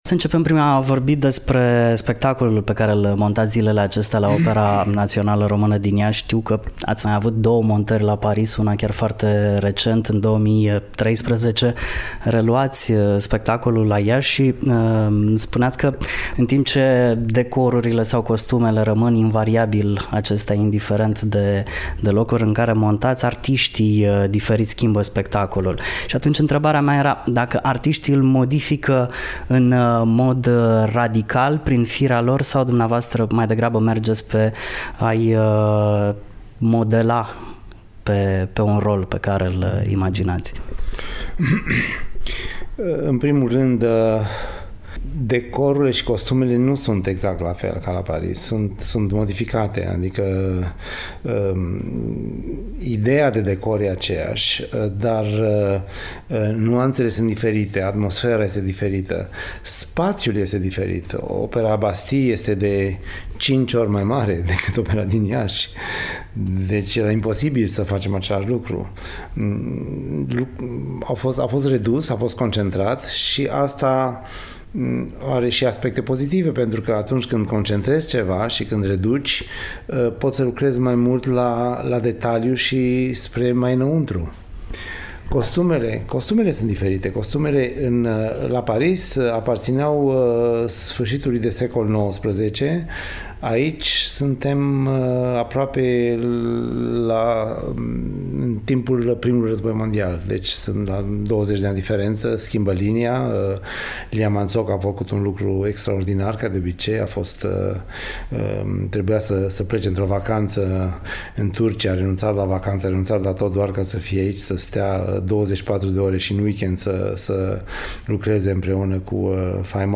(EXCLUSIV/ INTERVIU) Andrei Șerban, despre premiera spectacolului Lucia di Lammermoor - Radio Iaşi – Cel mai ascultat radio regional - știri, muzică și evenimente